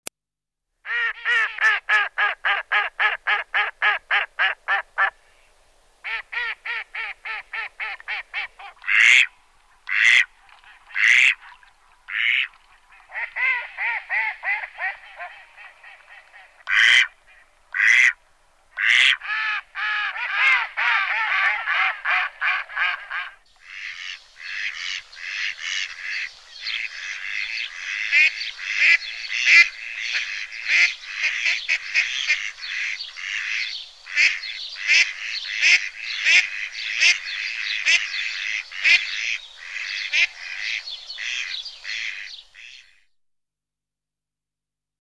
Звуки уток
Кряканье дикой утки